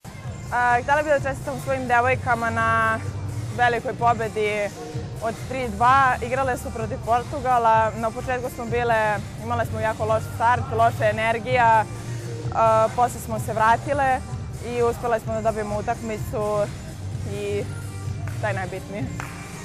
Izajva